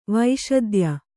♪ vaiśadya